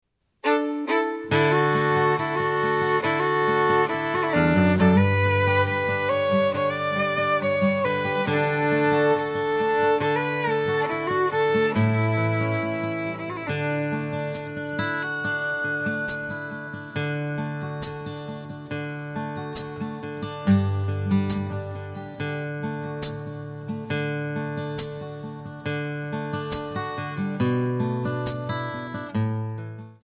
Backing track Karaoke
Oldies, Country, 1960s